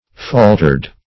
Falter \Fal"ter\, v. i. [imp.